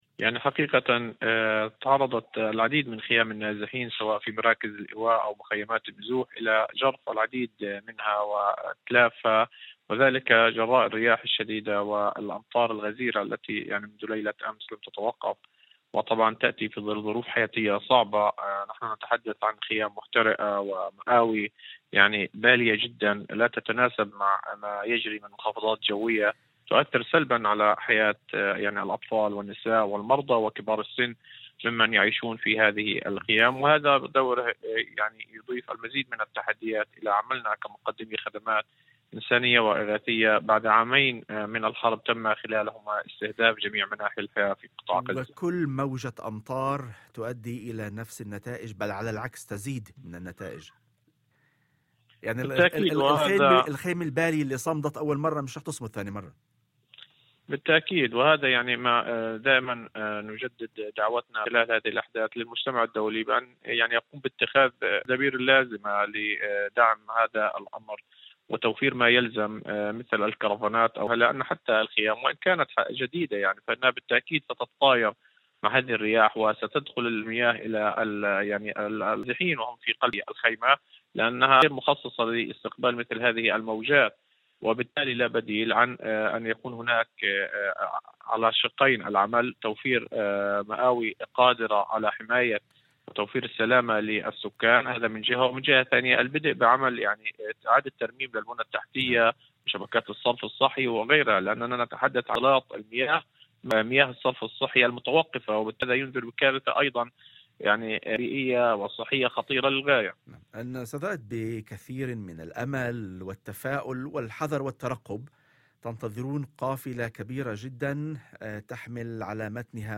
وأضاف في مداخلة هاتفية ضمن برنامج "يوم جديد"، على إذاعة الشمس، أن هذه الإمدادات تأتي في ظل تدهور حاد للمنظومة الصحية نتيجة الحرب والإجراءات المفروضة على المعابر، والتي حدّت بشكل كبير من دخول الأدوية والمستلزمات الطبية اللازمة لتلبية احتياجات المرضى والمصابين.